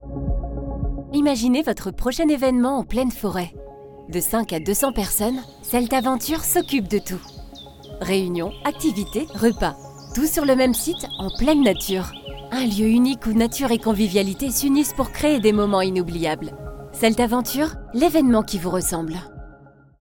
Jeune, Naturelle, Douce, Chaude, Commerciale
Commercial
Avec une voix moyenne, chaude et fluide, je donne vie à vos projets grâce à des narrations authentiques, captivantes et empreintes de sincérité.
Mon studio d’enregistrement professionnel garantit une qualité sonore optimale pour vos productions.